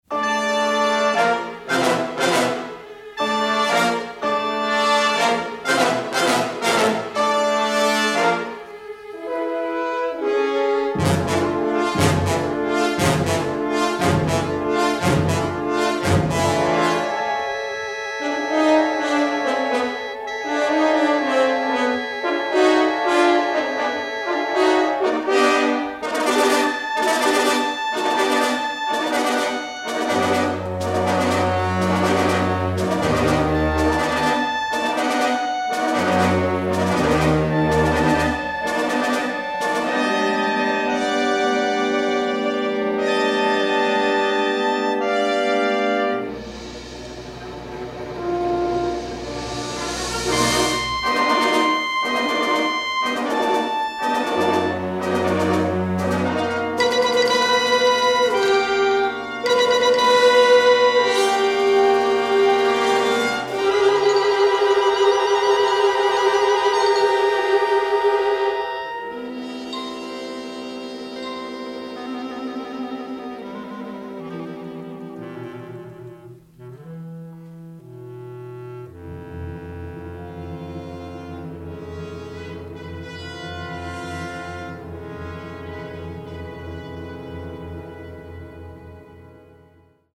remarkable orchestral score